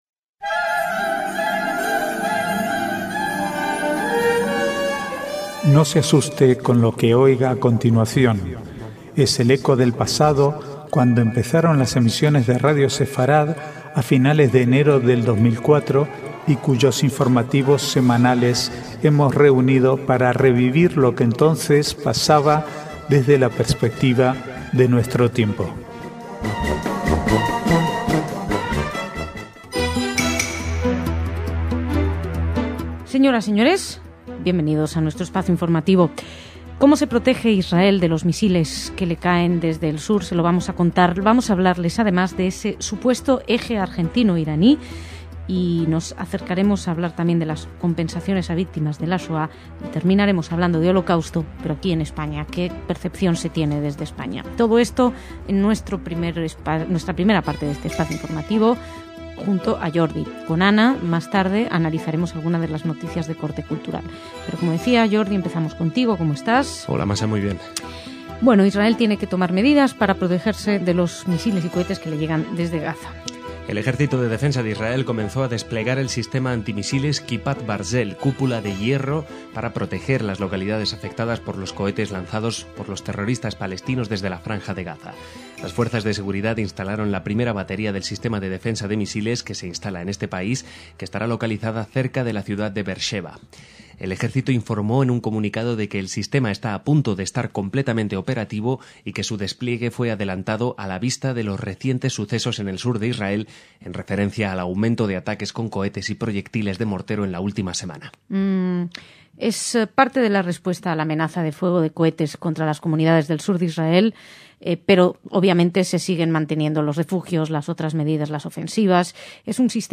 Archivo de noticias del 29/3 al 1/4/2011